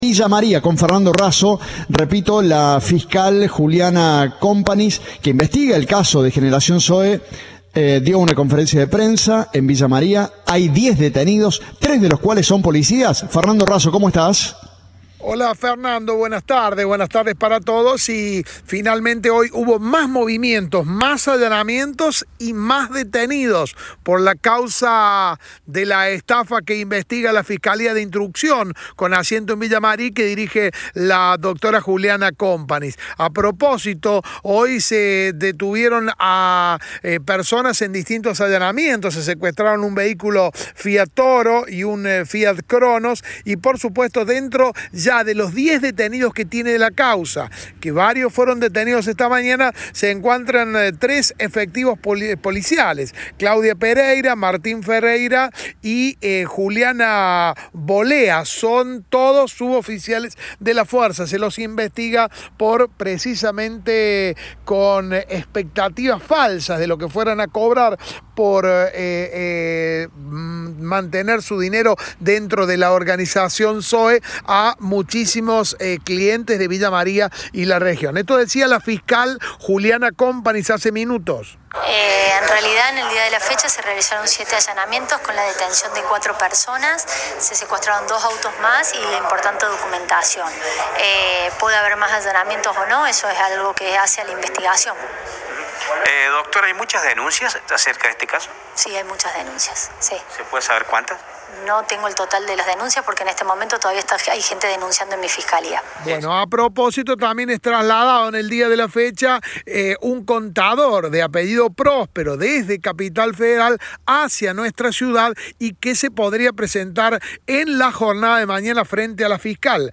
Informes